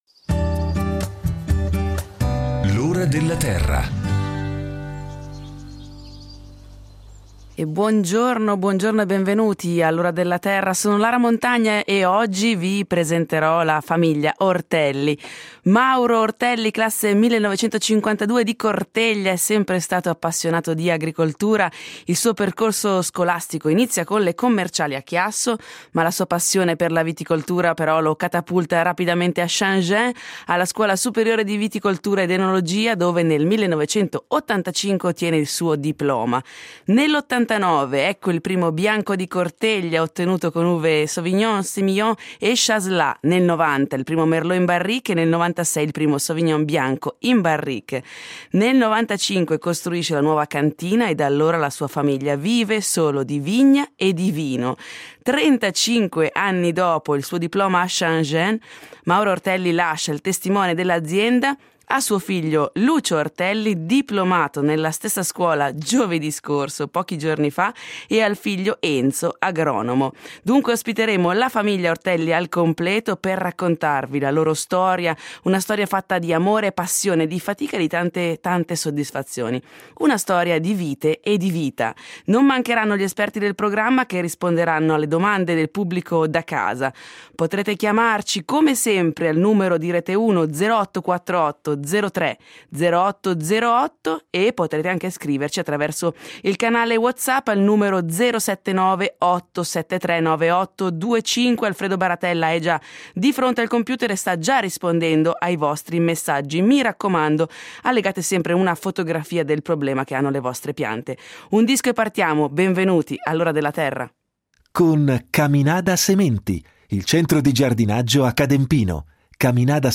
Una storia di vite e di vita. Non mancheranno gli esperti del programma, che risponderanno alle domande del pubblico da casa.